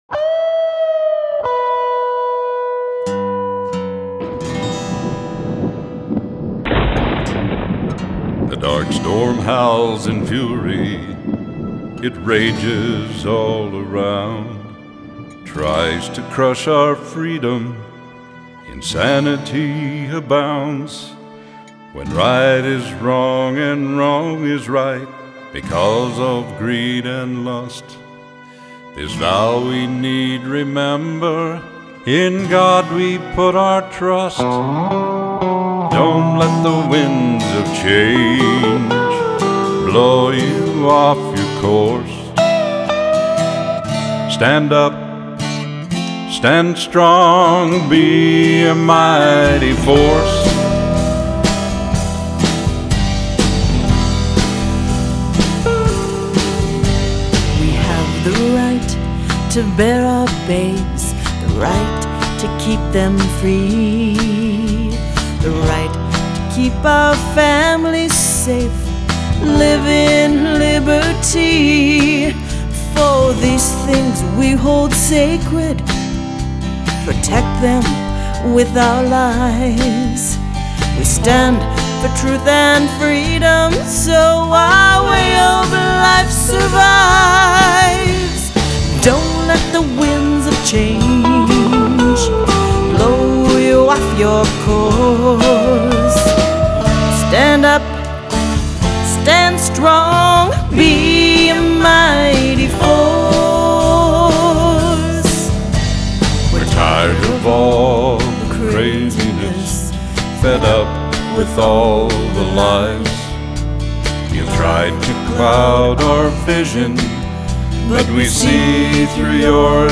Vocals
Recording: Sage Mountain Studio.